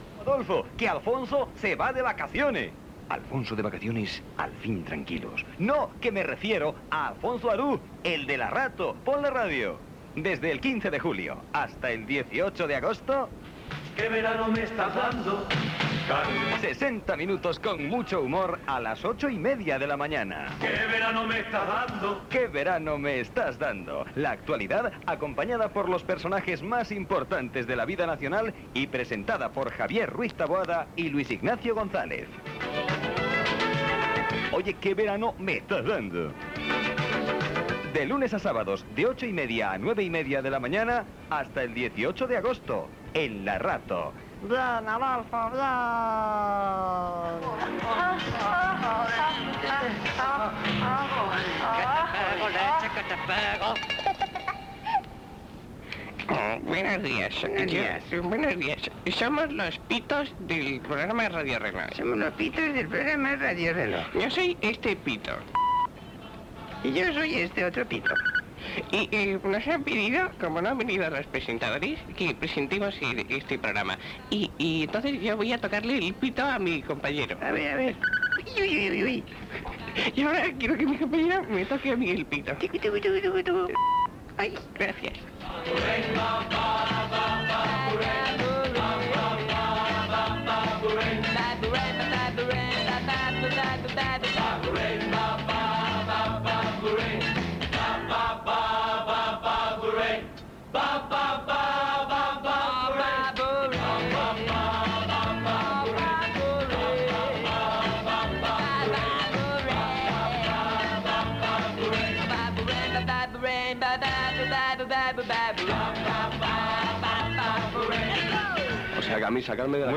Promoció del programa, paròdia dels senyals horaris, presentació, els embussos a les carreteres, entrevista a l'"Operación salida"
Entreteniment